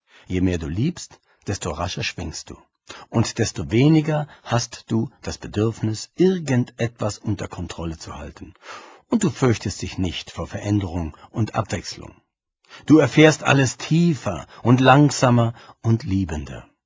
Hörbuch, 68 Minuten
Gelesen von Christian Anders